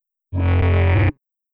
alien_beacon.wav